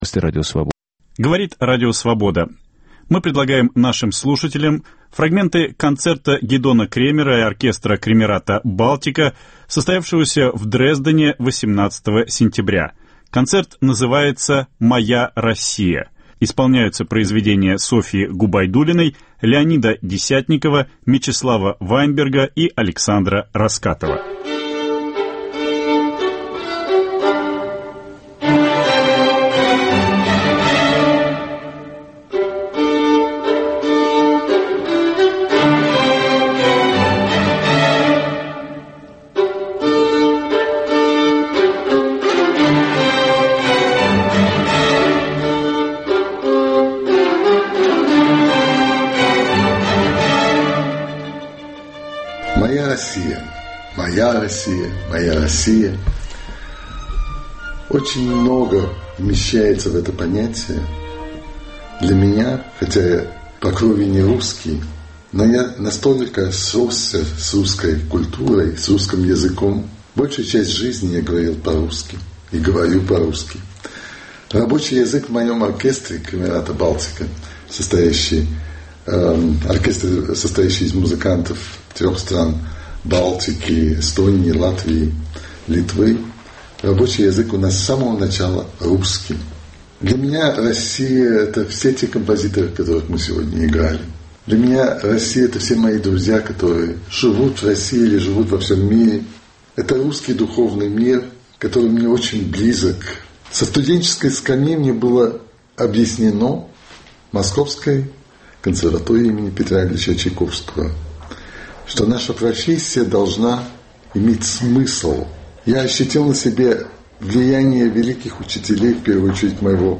В Дрезденской опере состоялся концерт Гидона Кремера и его оркестра "Кремерата Балтика". Программа концерта была заменена в связи с событиями на Украине и в России, в его рамках состоялась беседа Гидона Кремера и композитора Леонида Десятникова о ситуации в России.